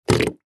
Звуки пердежа, пукания